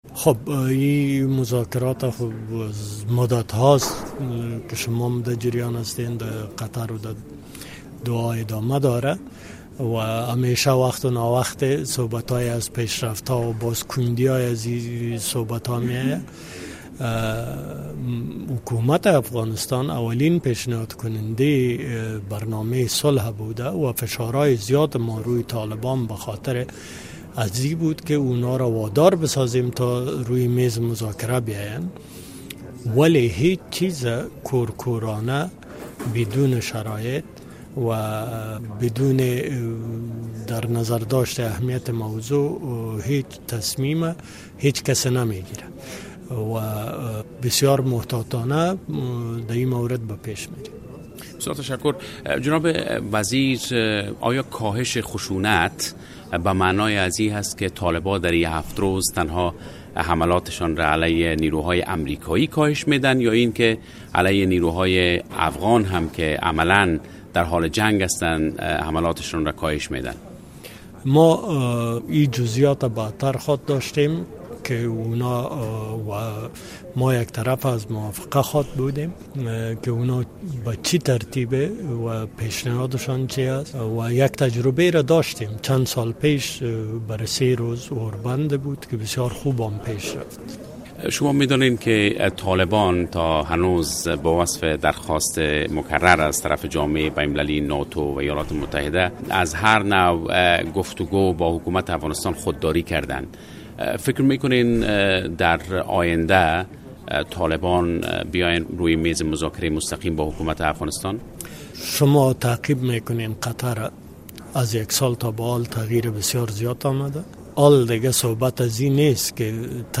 اسدالله خالد سرپرست وزارت دفاع افغانستان حین مصاحبه با رادیو آزادی در بروکسل. فبروری 13 2020
آقای خالد در پایان نشست دو روزۀ وزیران دفاع ناتو در بروکسل با رادیو آزادی صحبت کرد.